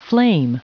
Prononciation du mot flame en anglais (fichier audio)
Prononciation du mot : flame